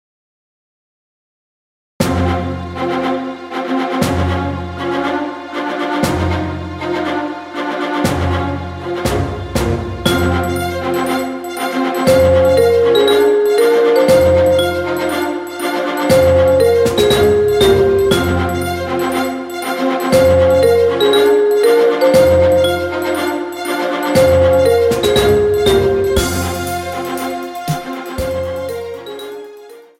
Demonstration